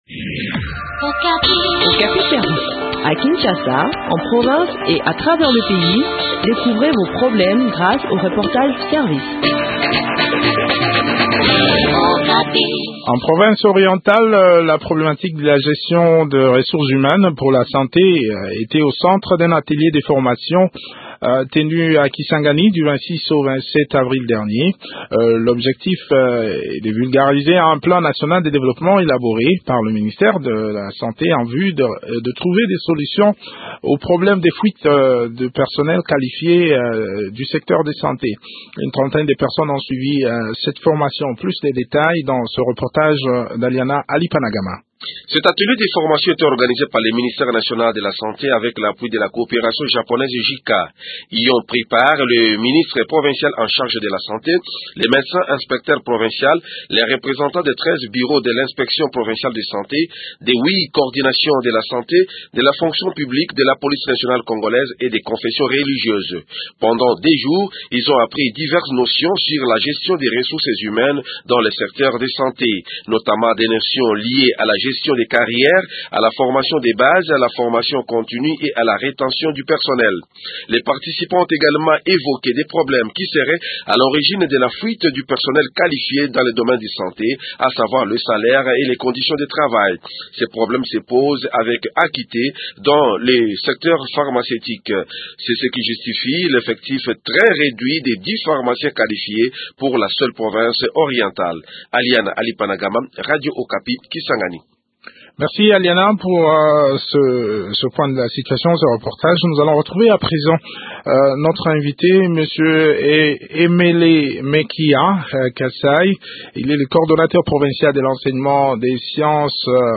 Quelles sont les conclusions de ce séminaire atelier ? Eléments de réponse dans cet entretien